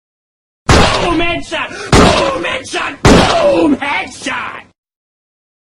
3× boom head shot - кнопка мгновенного звукового эффекта | Myinstants
boom_headshot_sound_effect256kbps.mp3